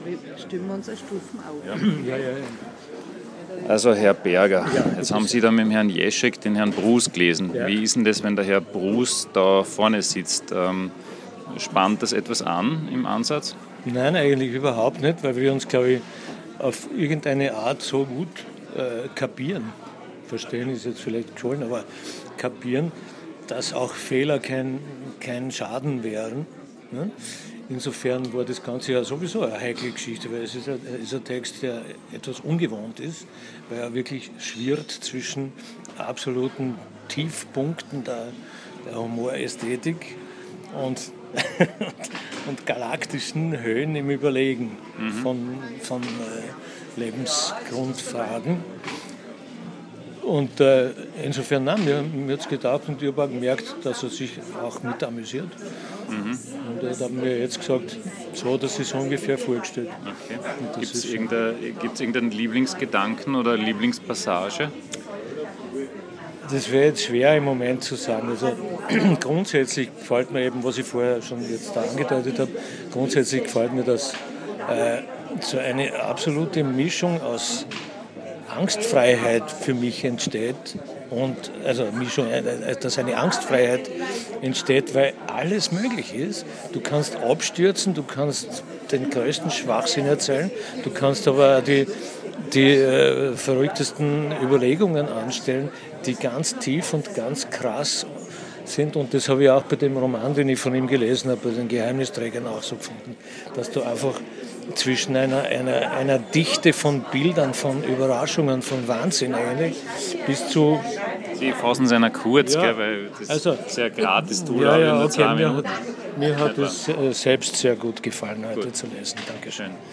Wolfram Berger las Brus